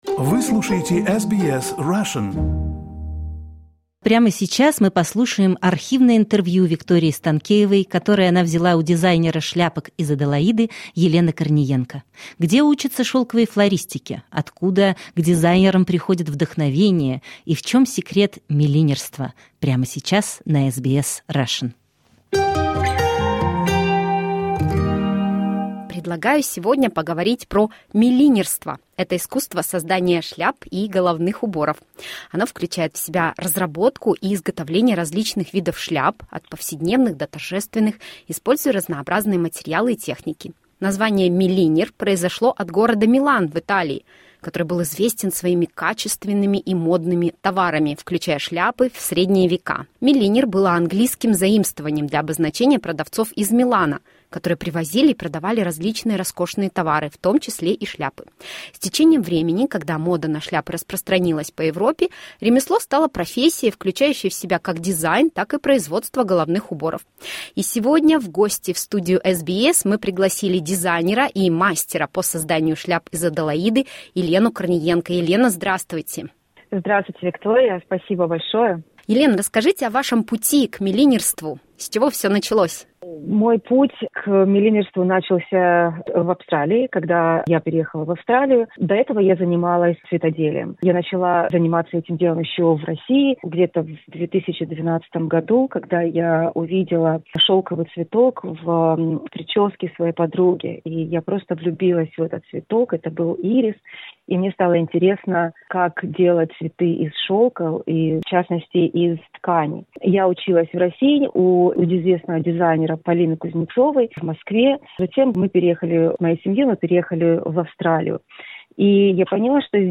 в интервью